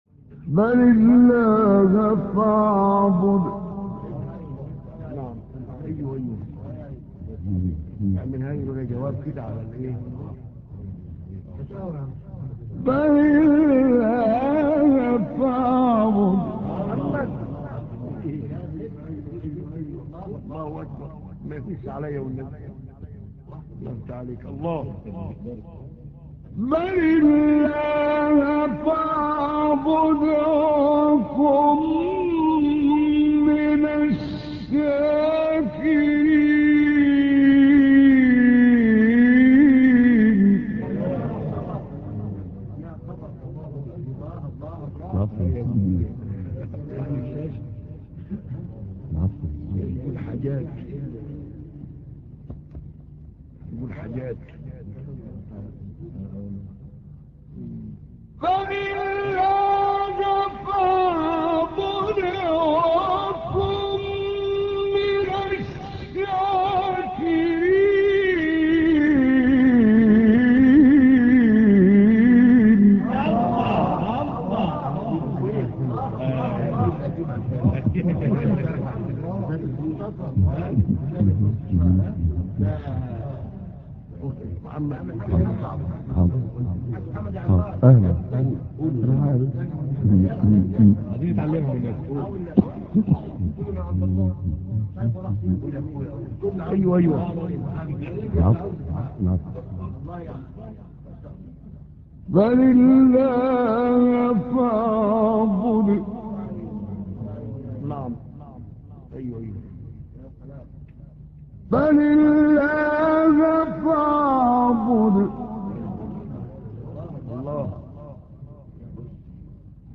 سوره : زمر آیه: 66-67 استاد : محمد عمران مقام : سه گاه قبلی بعدی